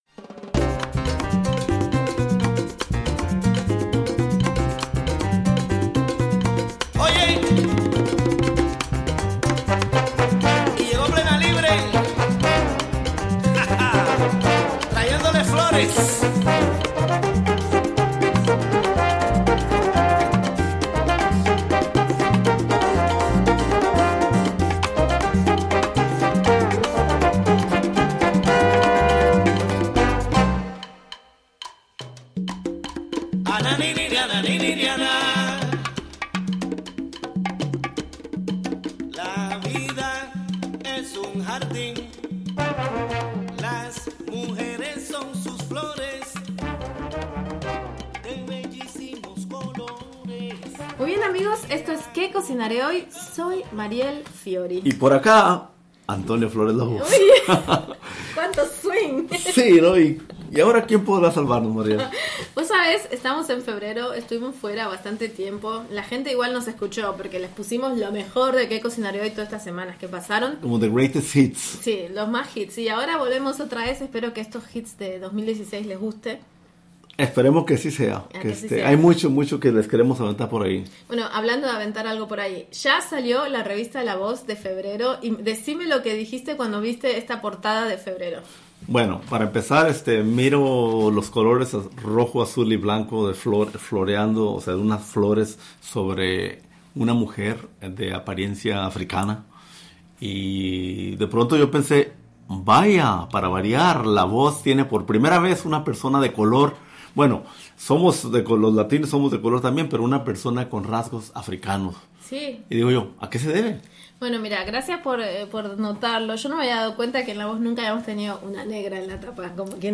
In this broadcast, a conversation